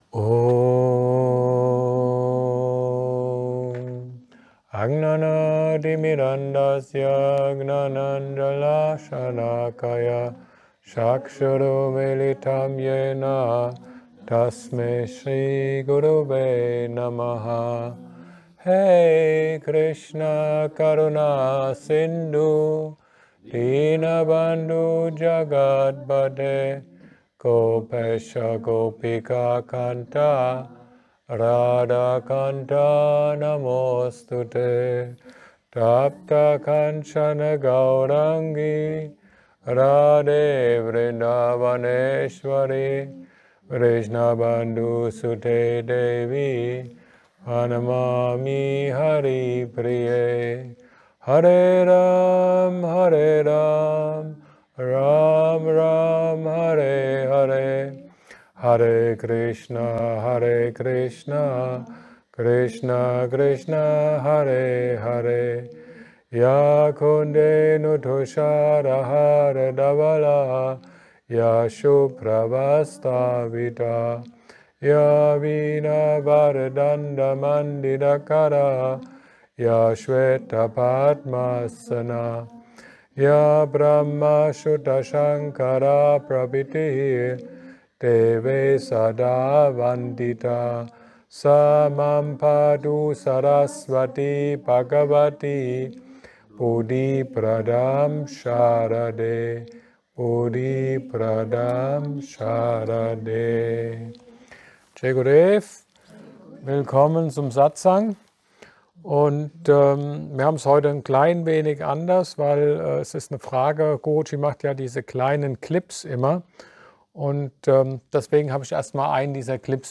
Satsang